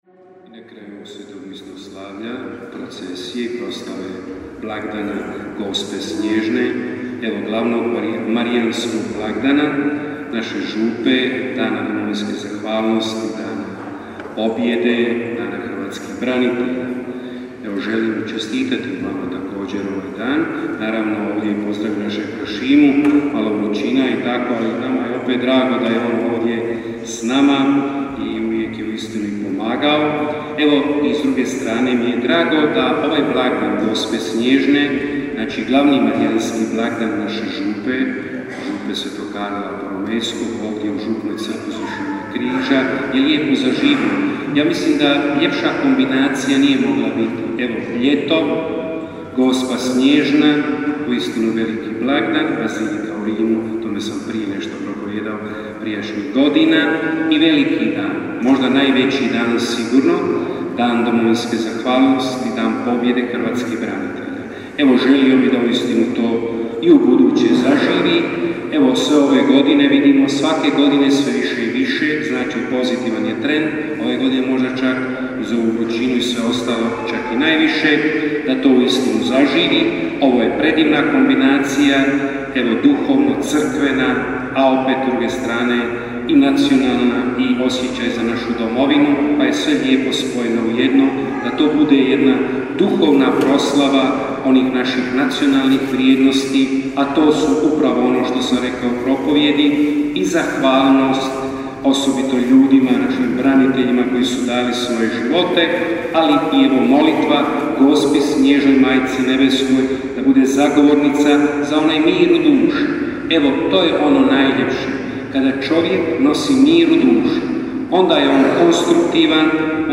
Svetkovina Gospe SNJEŽNE, mjesto događanja Župna Crkva (prije svete mise procesija).
RIJEČ O GOSPI SNJEŽNOJ (zahvala svima koji su sudjelovali u procesiji):